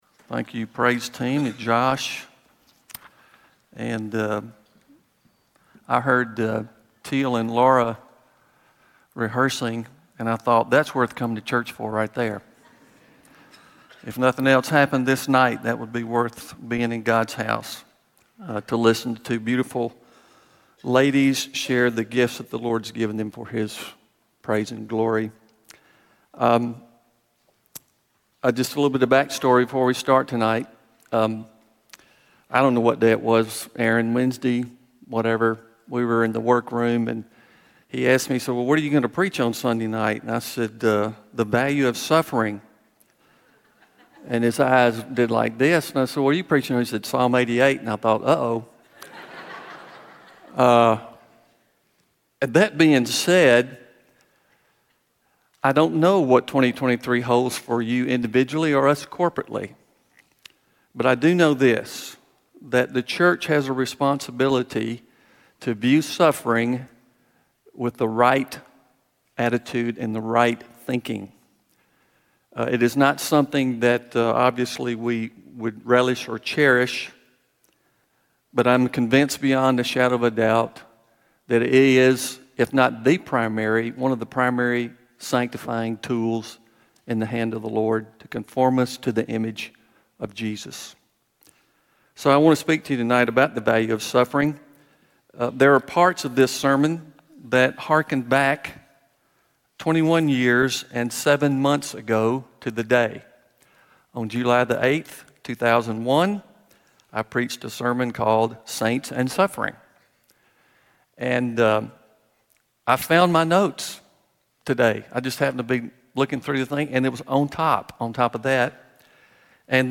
FOR SECURITY REASONS, THIS SERMON IS AUDIO ONLY